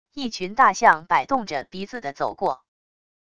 一群大象摆动着鼻子的走过wav音频